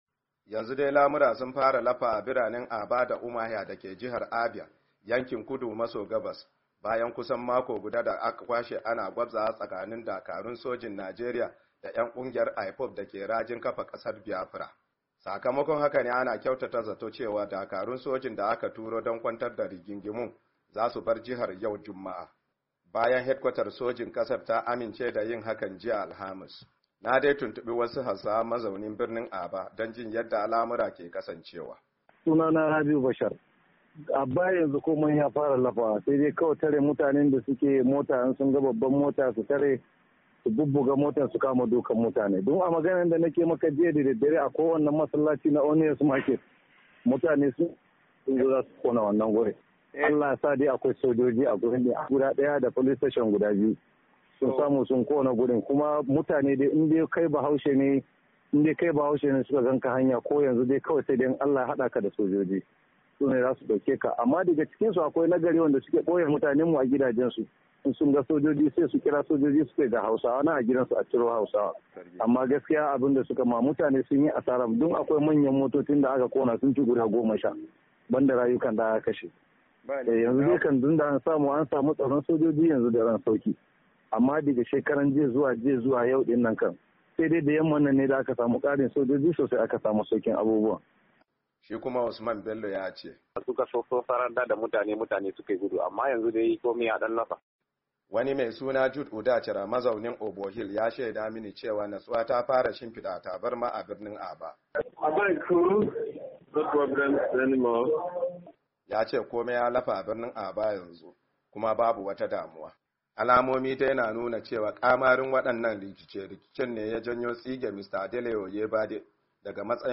Na dai tuntubi wasu Hausawa mazau nan birnin Aba, don jin yadda al’amura ke kasancewa.